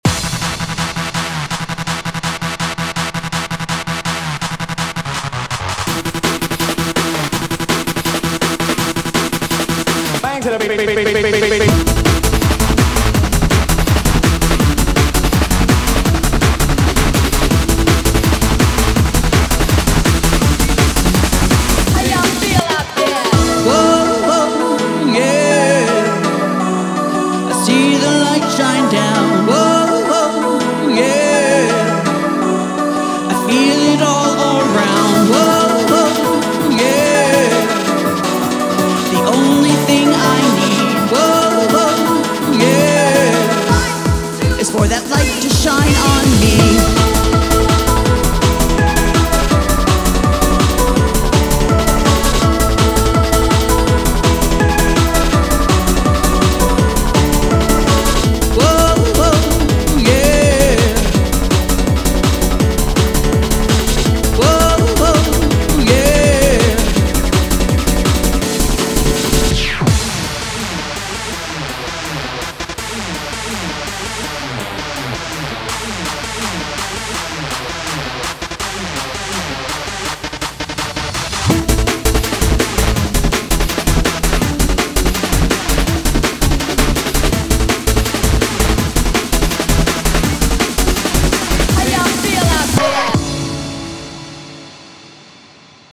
BPM165
Audio QualityMusic Cut
HAPPY HARDCORE